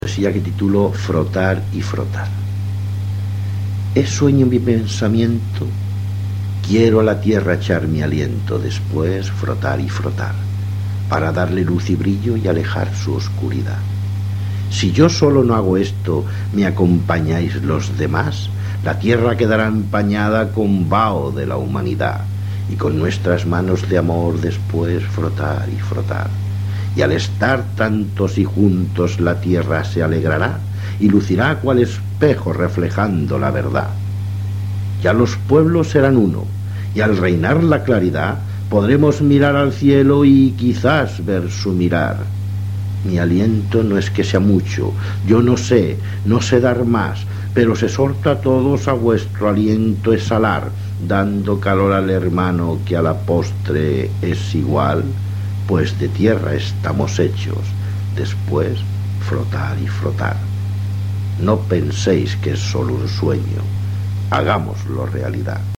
Poesia